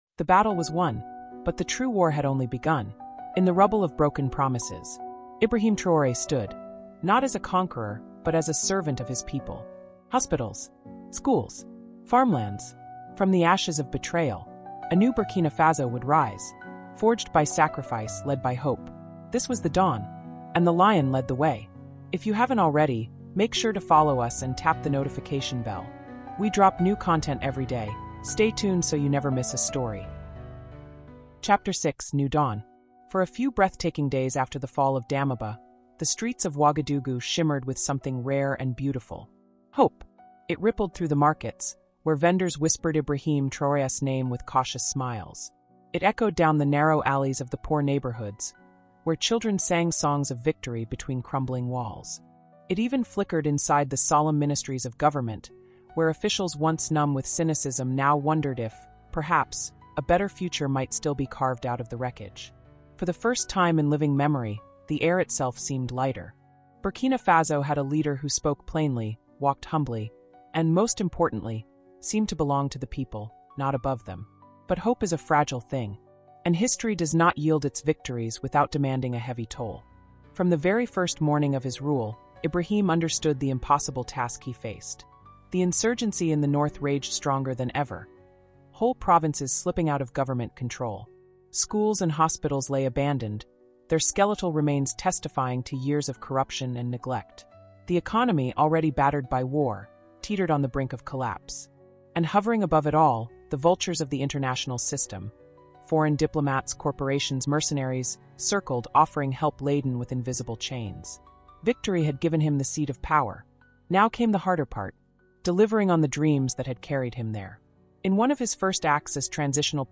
Captain Ibrahim Traoré: africa cultural diplomacy (ch6) | Audiobook